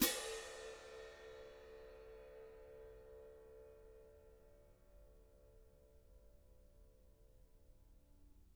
cymbal-crash1_pp_rr2.wav